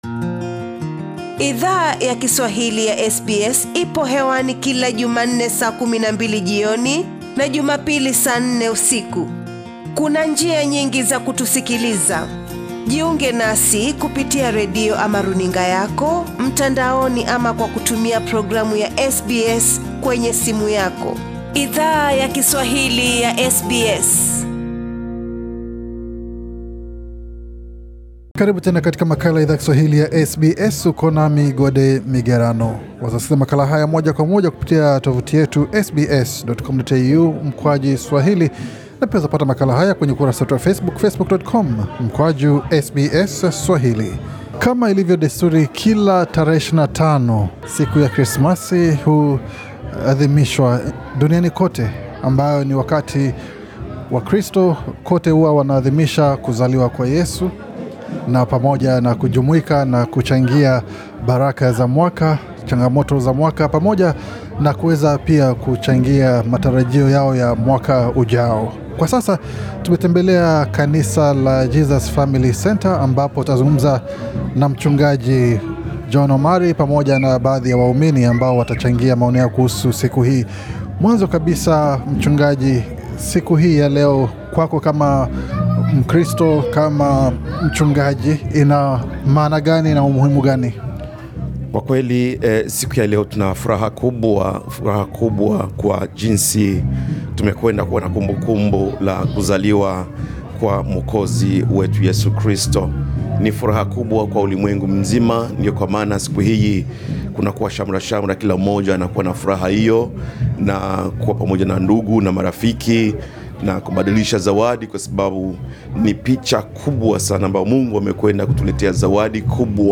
Kila tarehe 25 Disemba watu wengu duniani kote, hujumuika katika sherehe za krismasi pamoja na familia na marafiki wao. SBS Swahili ilitembelea kanisa la Jesus Family Centre, ambalo liko katika kitongoji cha Cabramatta, NSW ambako baadhi ya waumini walichangia maoni yao nasi kuhusu krimasi.